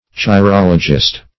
Search Result for " chirologist" : The Collaborative International Dictionary of English v.0.48: Chirologist \Chi*rol"o*gist\, n. One who communicates thoughts by signs made with the hands and fingers.